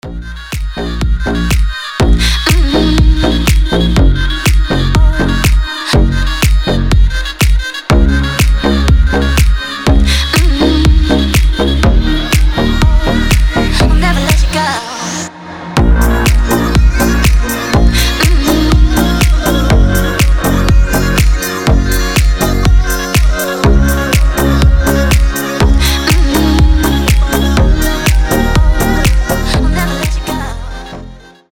• Качество: 320, Stereo
гитара
deep house
женский голос
басы
восточные
Ритм натянутой струны) Звучит строго и красиво